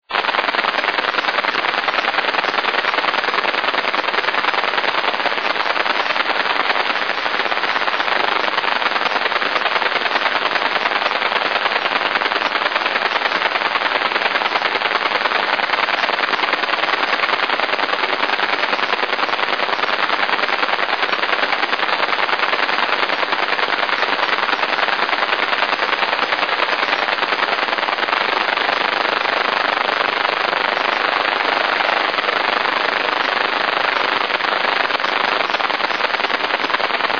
des bruits de marteaux-piqueurs ou d'hélicoptère se font entendre avant d'atteindre un régime "de croisière" une minute après ( crépitements).
Si l'ensoleillement n'est pas suffisant, ces bruits varient en fréquence (plus ou moins rapide) et en amplitude.
- Audio 1 (10 MHz AM sur Kenwood TS-50. Niveau S7 / 22dBµV / -85dBm / 12,6µV)
10MHz_S7b.mp3